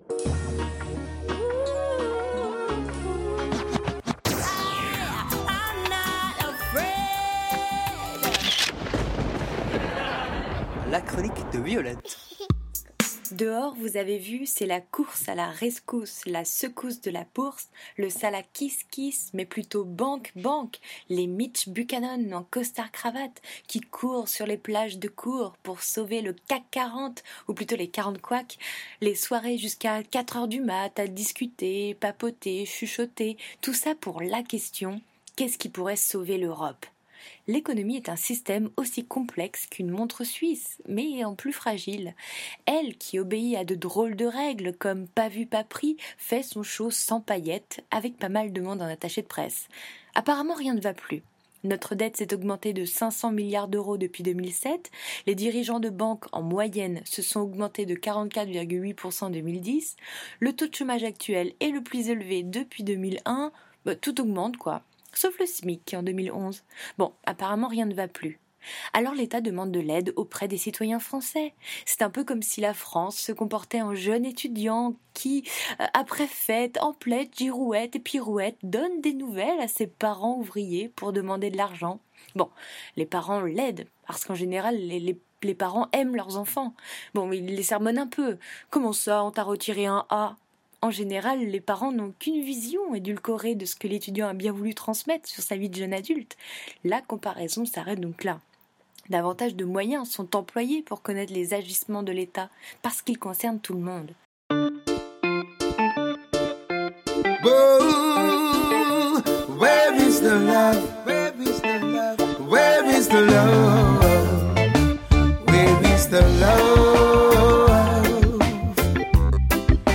Illustration sonore: